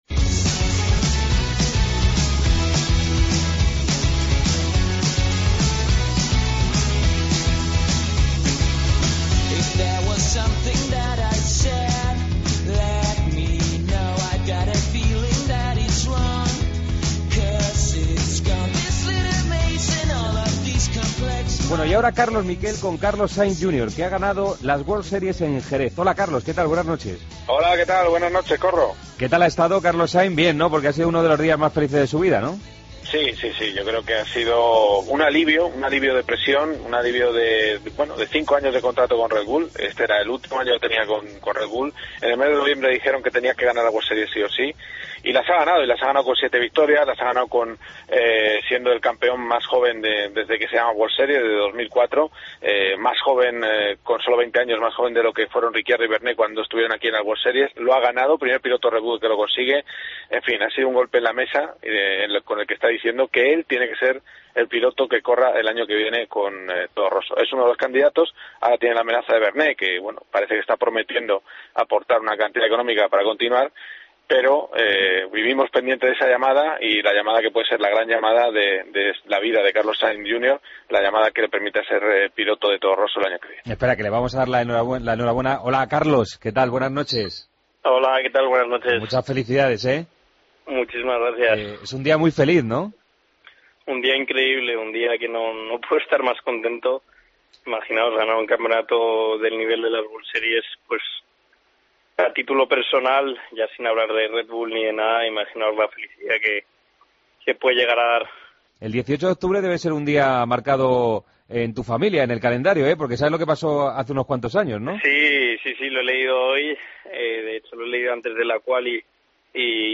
Redacción digital Madrid - Publicado el 19 oct 2014, 02:41 - Actualizado 14 mar 2023, 04:52 1 min lectura Descargar Facebook Twitter Whatsapp Telegram Enviar por email Copiar enlace Tiempo para el motor. Entrevista con el campeón de la World Series by Renault, Carlos Sáinz Jr.